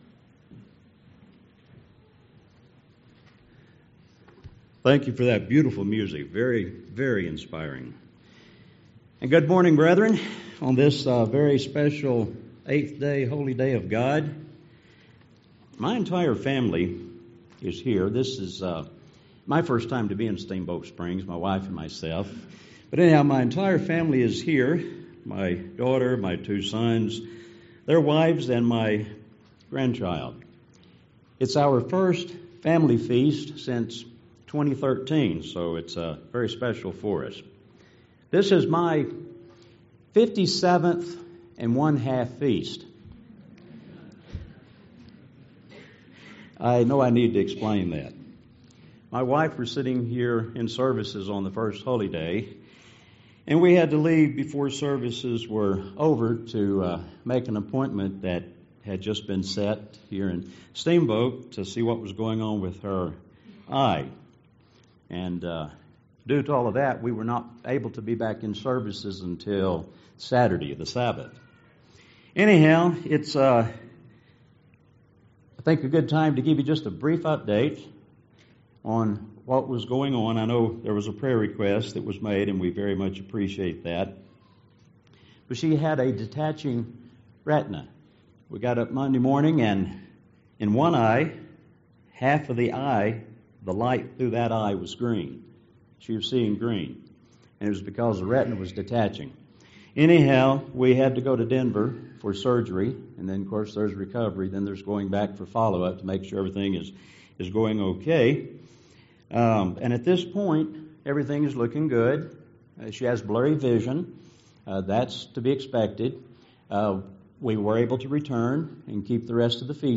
This sermon was given at the Steamboat Springs, Colorado 2018 Feast site.